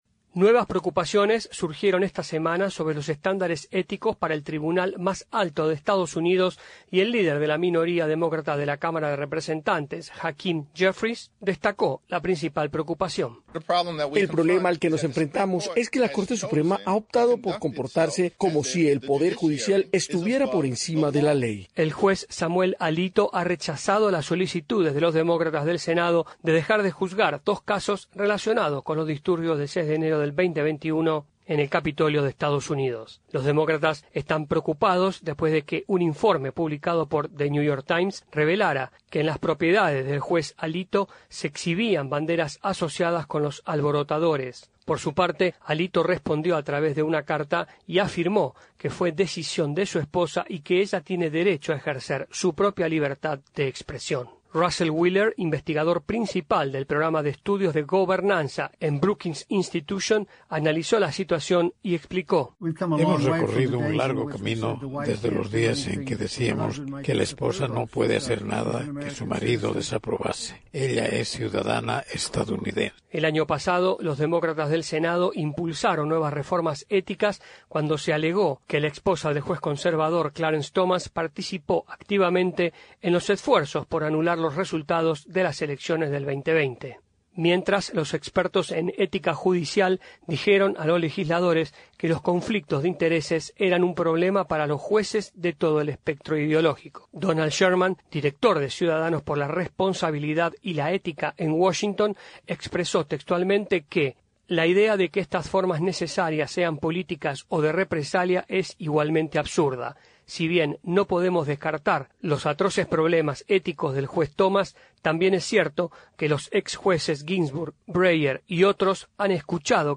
El debate sobre la ética en la Corte Suprema de Estados Unidos se renovó esta semana cuando los demócratas del Congreso pidieron al juez conservador Samuel Alito que se abstuviera de dos casos relacionados con las elecciones de 2020. El reporte